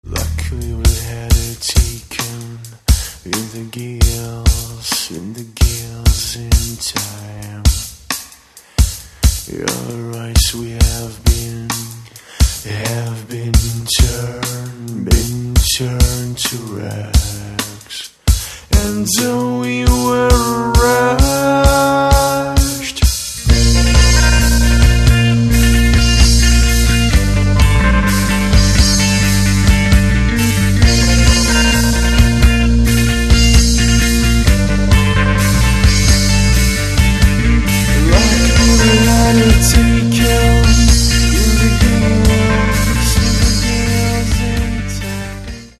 Catalogue -> Rock & Alternative -> Energy Rock